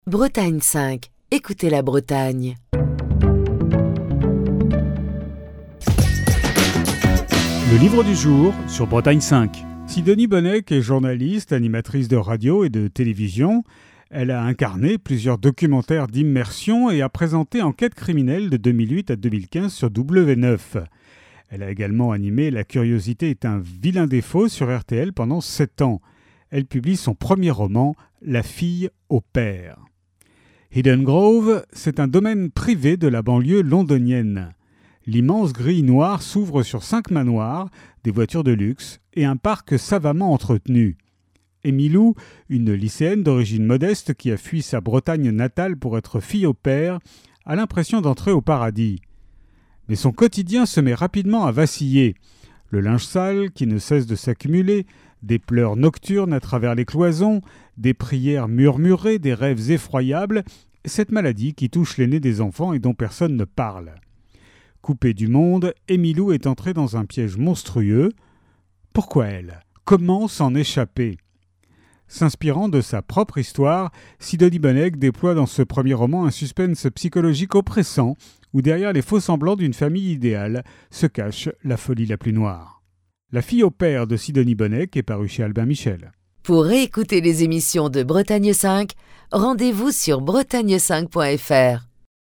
Chronique du 15 mai 2025.